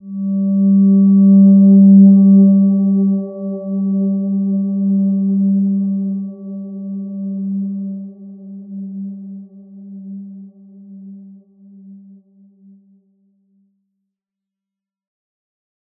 Silver-Gem-G3-mf.wav